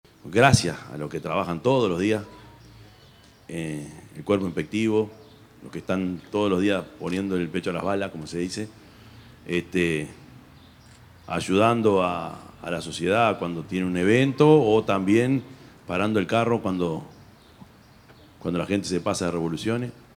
yamandu_orsi_intendente_de_canelones_13.mp3